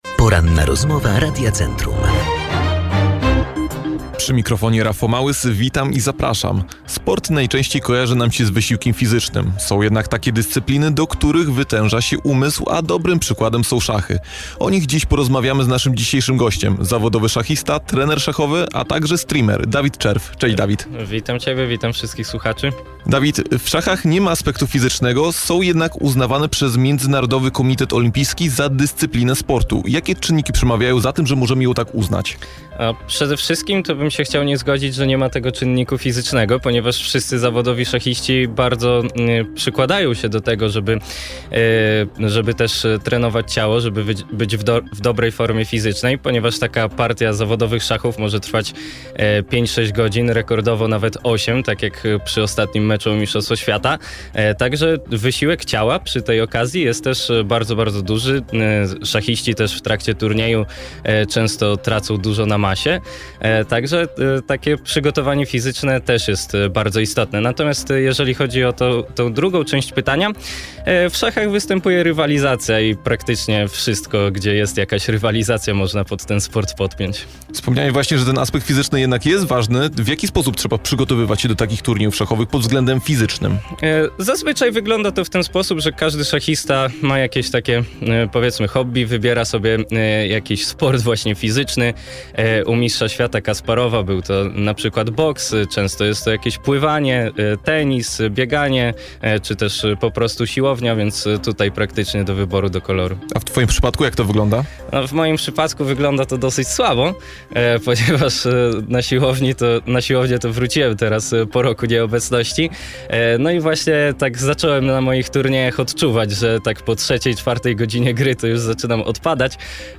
Rozmowa-po-edycji-1.mp3